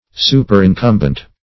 Superincumbent \Su`per*in*cum"bent\, a. [L. superincumbens, p.